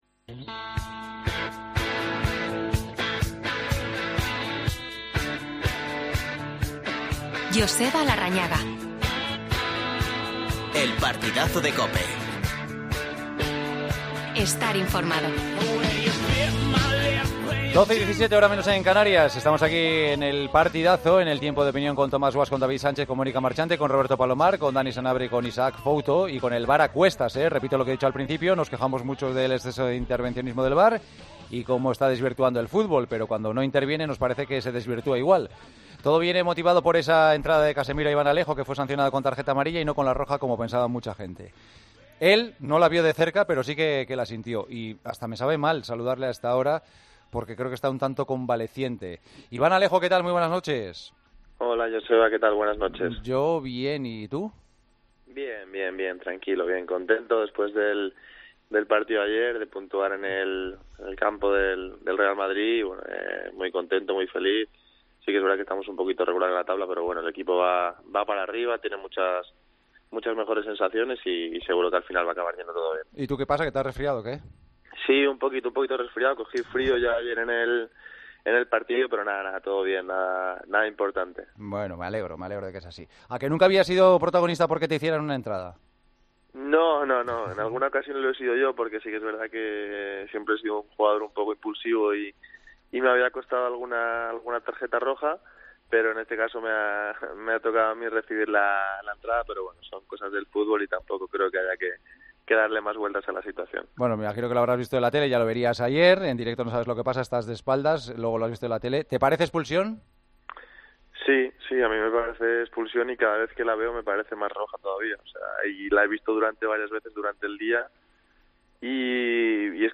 AUDIO: Continúa el Tiempo de Opinión. Entrevista a Iván Alejo, jugador del Cádiz. El Real Madrid ya escucha ofertas por Hazard.